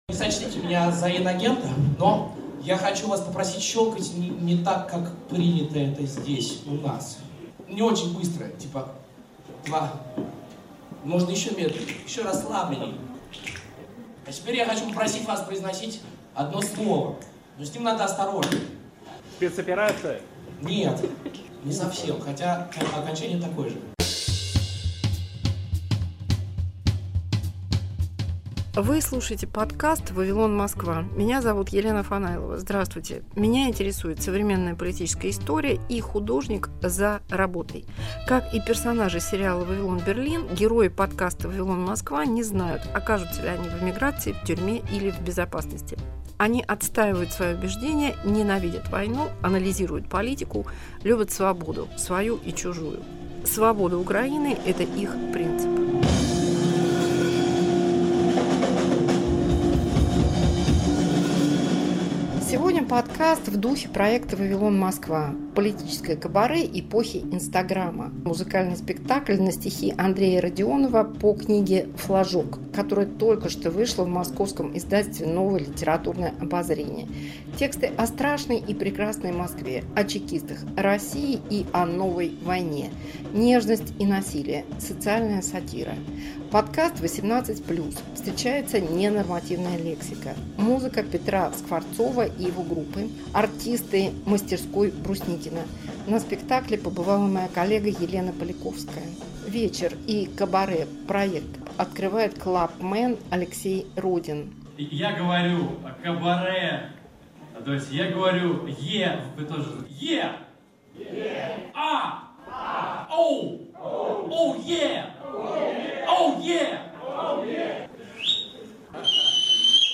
Музыкальный спектакль